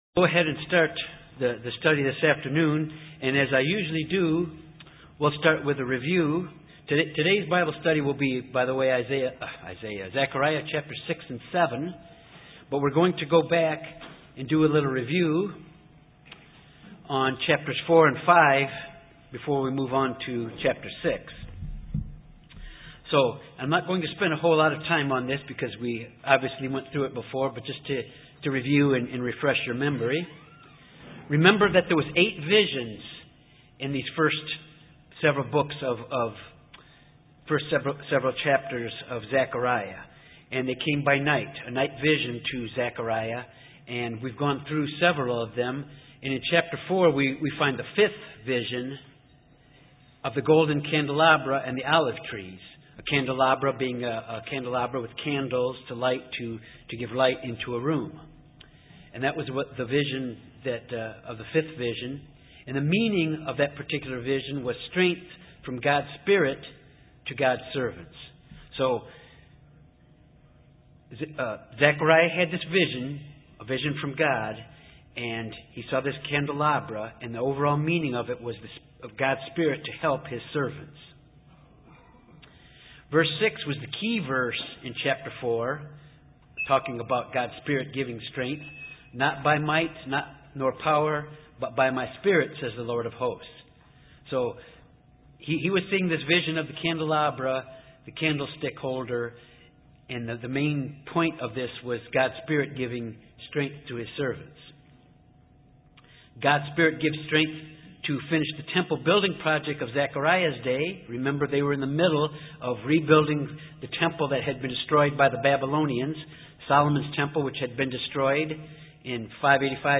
Bible Study Notes